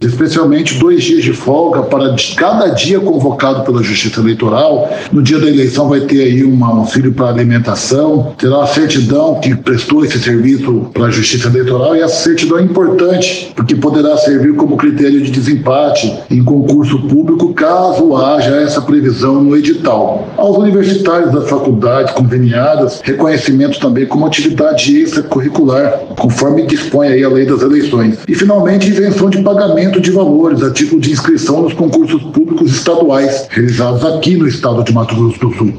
Em entrevista à FM Educativa MS 104.7, o juiz auxiliar da presidência do TRE-MS, Luiz Felipe de Medeiros Vieira, explicou que o cadastro pode ser feito pela internet.